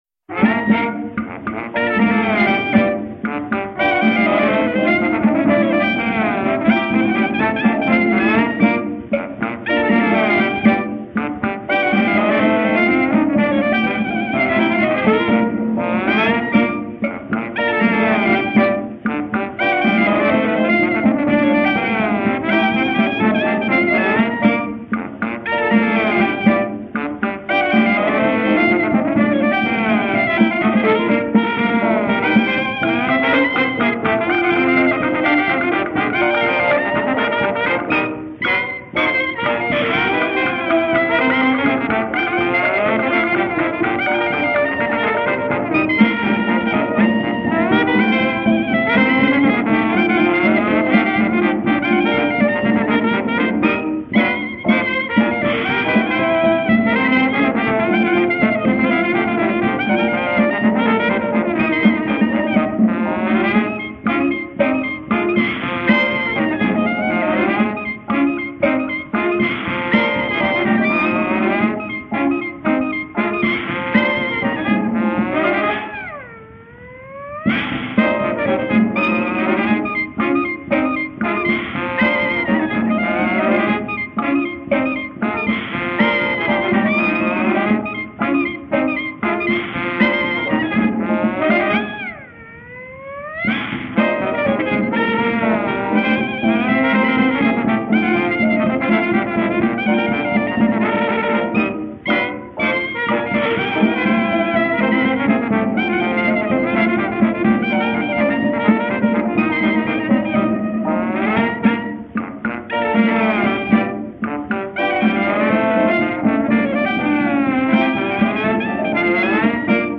piano
trombone
cornet